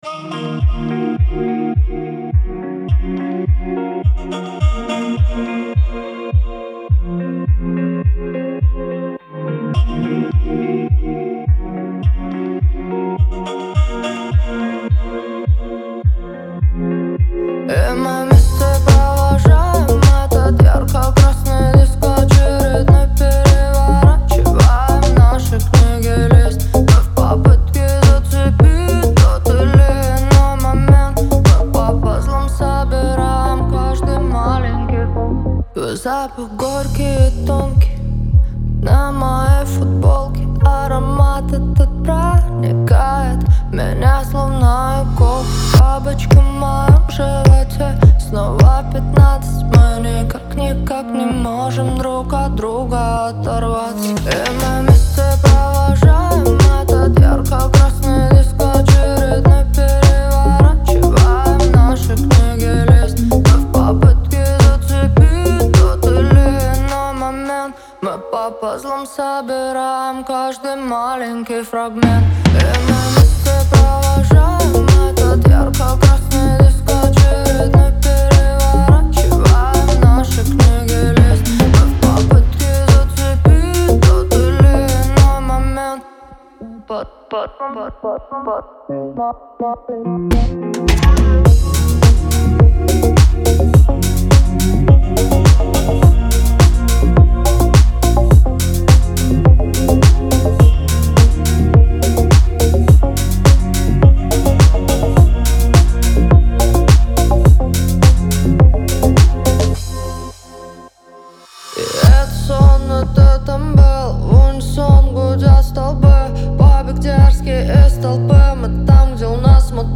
это зажигательный трек в жанре поп-рок